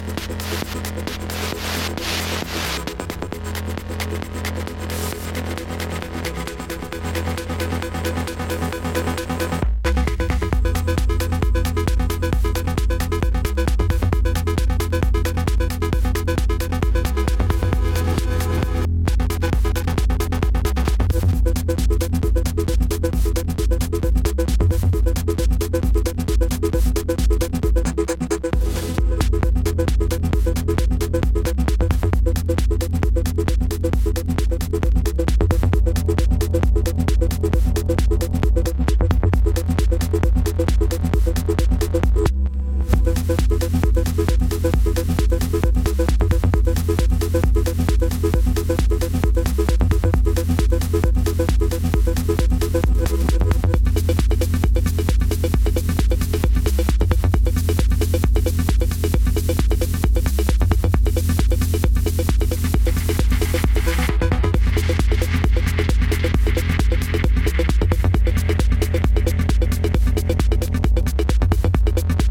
プログレッシブ・ハウス